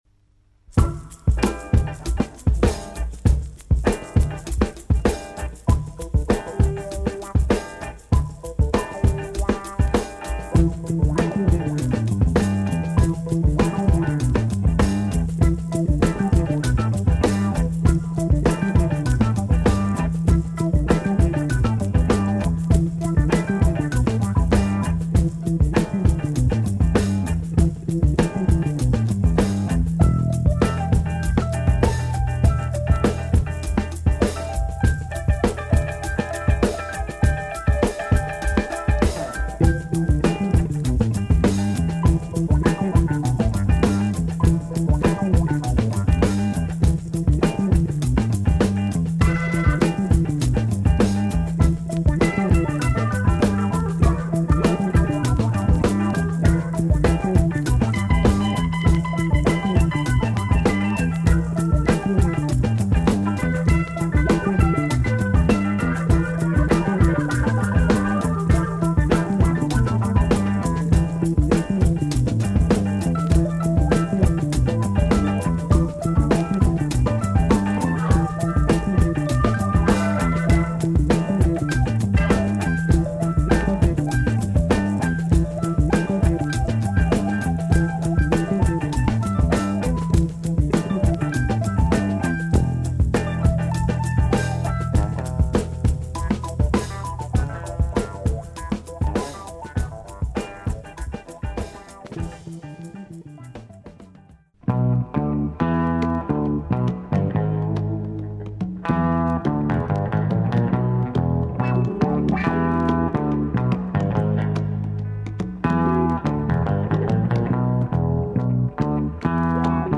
library albums
Heavy psych groove here !